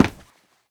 scpcb-godot/SFX/Step/Run4.ogg at 423912bbded30835f02a319640a5813ecd8cd6ca